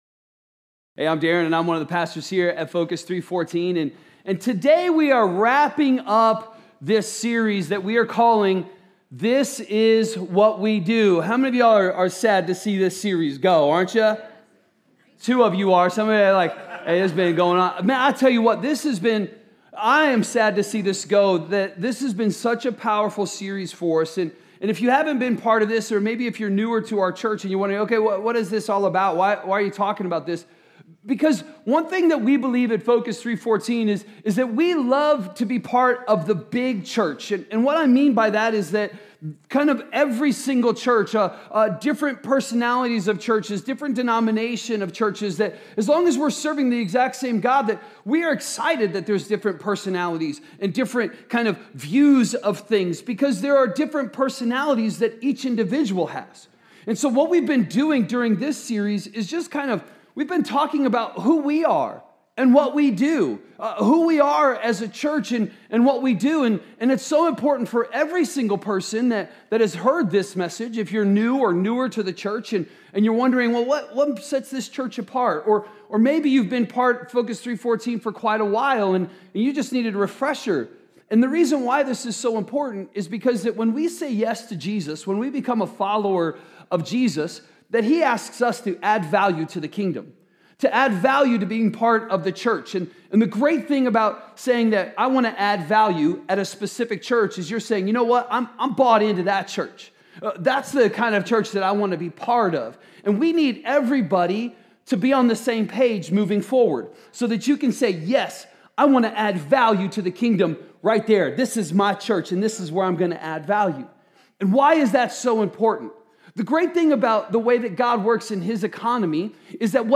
A message from the series "This Is What We Do."